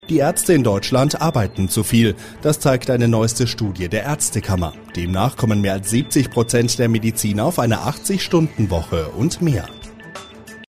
Werbesprecher
Sprecherdemo